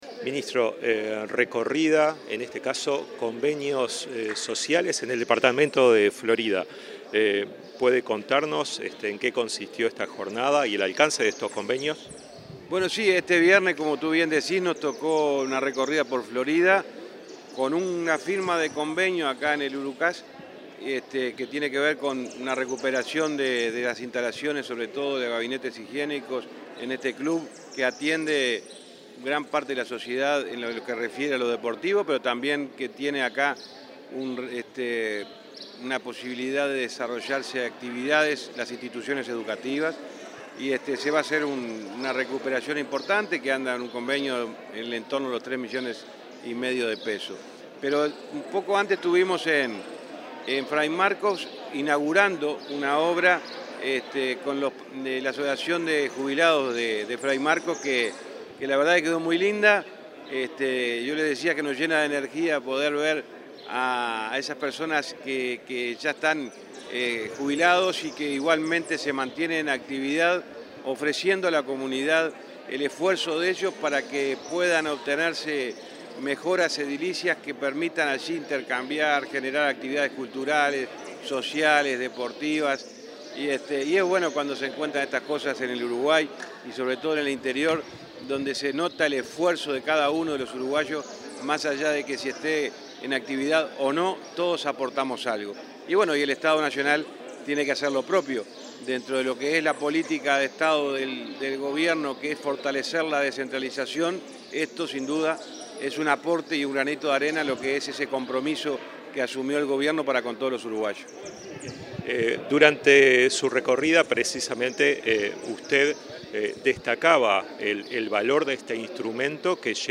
Entrevista al ministro de Transporte, José Luis Falero